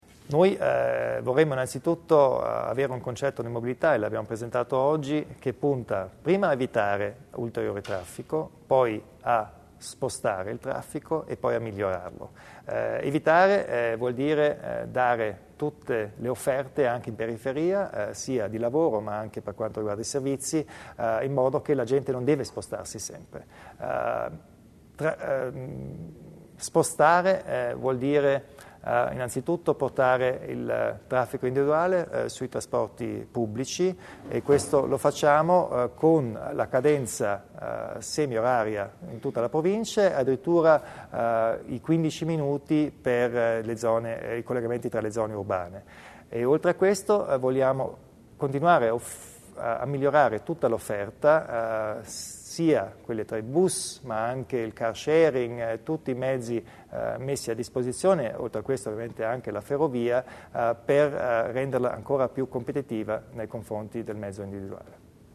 Le misure previste dalla giunta provinciale nel campo della mobilità sono state illustrate oggi (3 gennaio) nel corso di una conferenza stampa